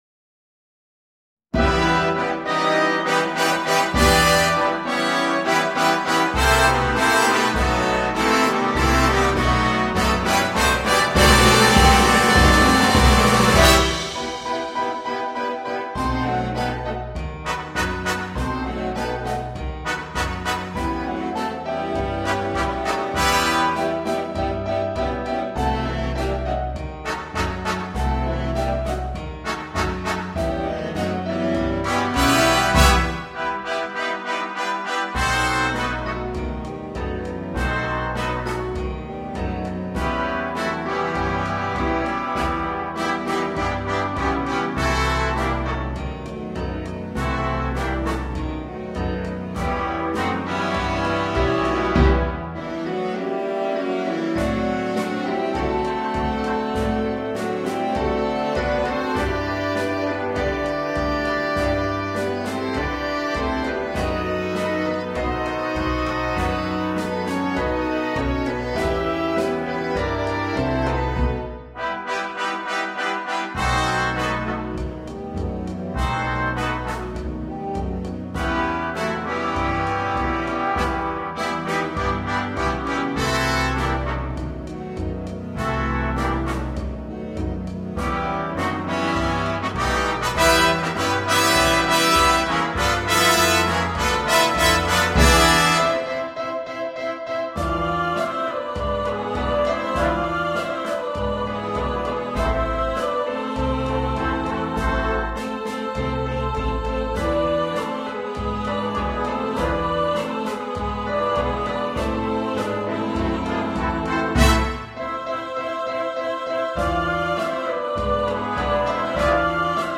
Ноты Slow dance для биг-бэнда.